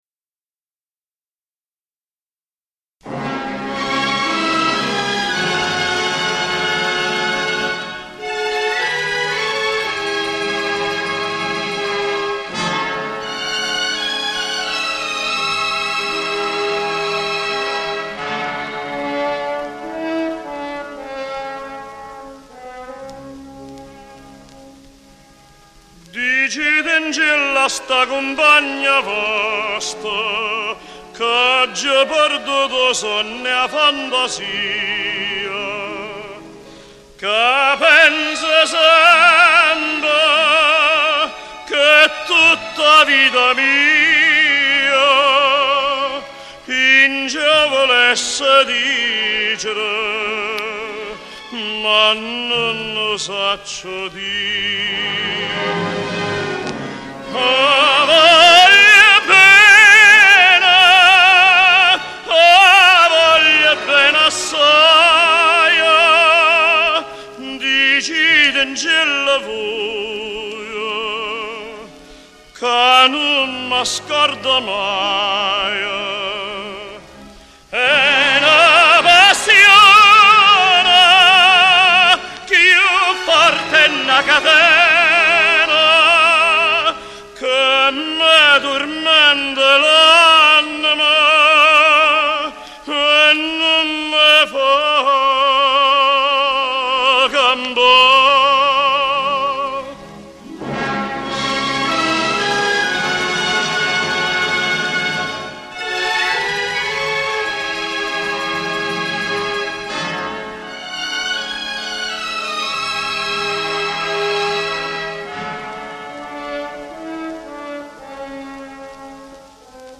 con accompagnamento orchestrale e coro